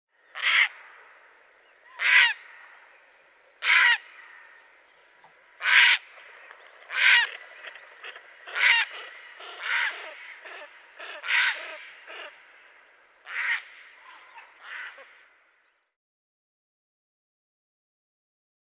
Gråhäger
Ardea cinerea
Ljud-Gra-hager.mp3